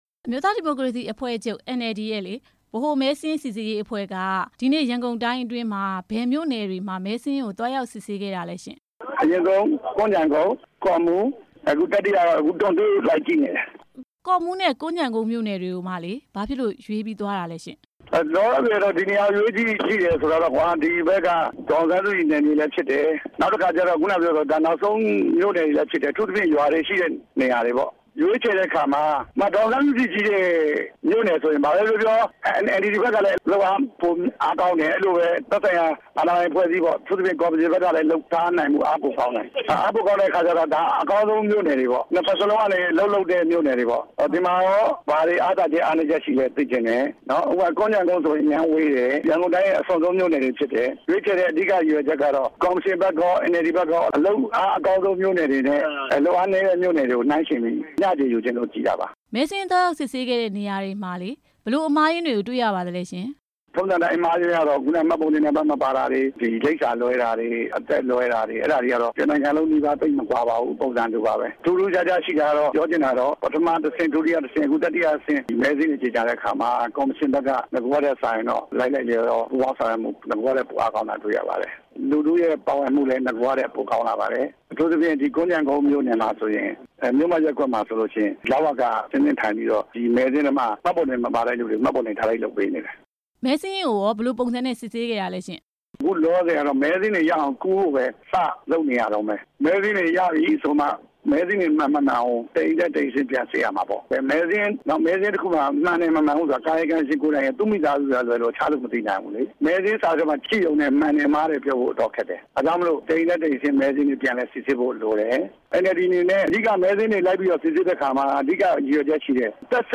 ဆက်သွယ် မေးမြန်းထားပါတယ်။